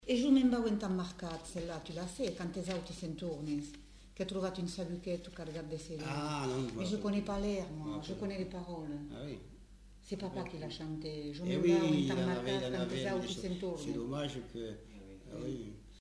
Formulette enfantine